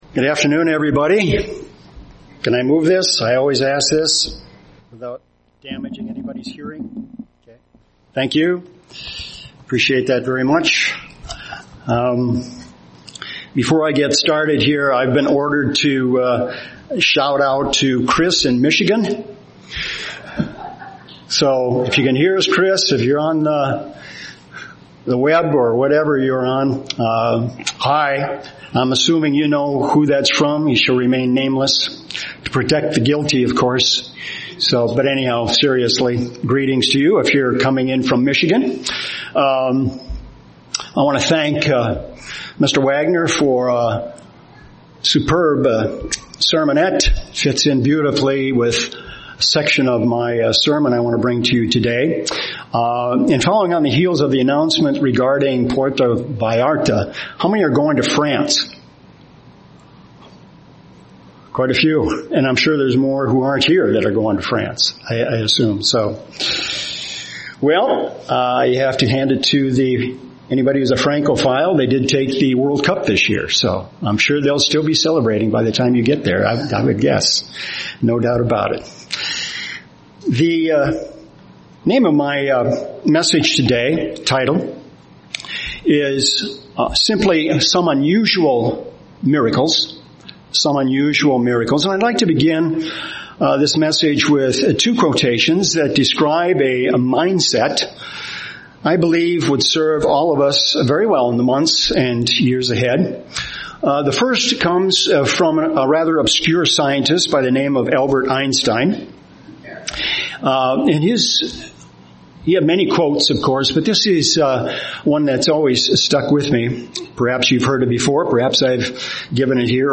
Given in Twin Cities, MN
UCG Sermon Miracles God's plan Studying the bible?